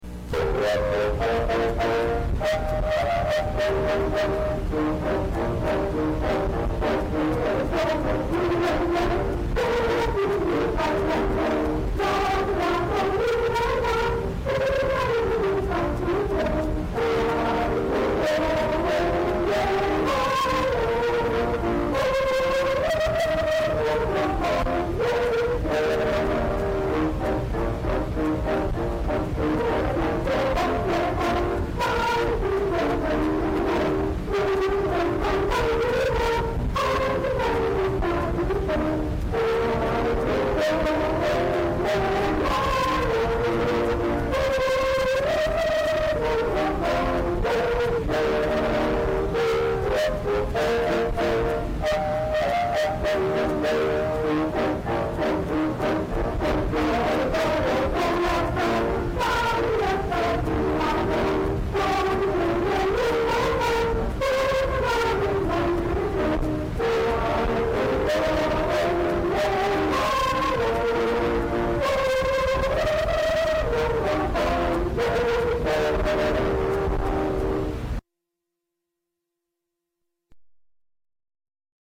Saemaul Undong Song from the radio to cassette, then from cassette to computer.